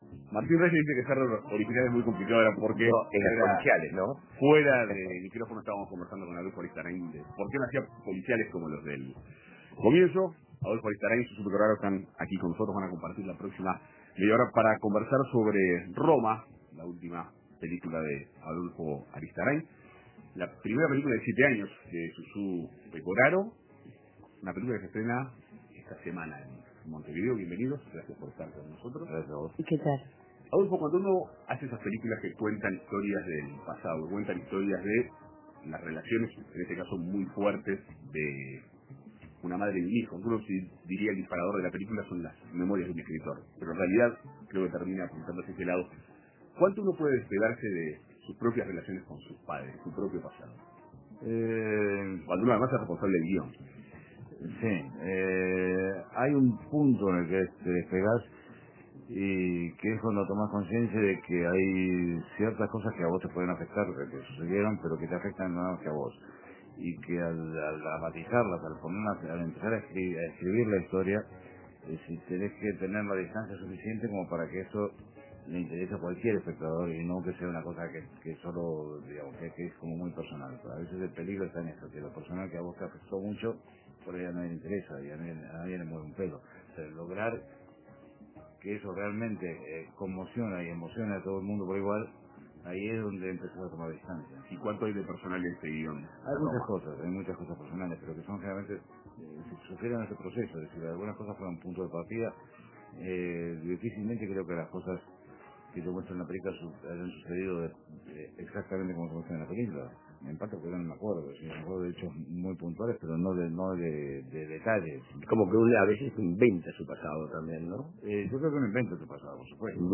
Roma. Susu Pecoraro y Adolfo Aristarain, actriz y director, hablan de la nueva película argentina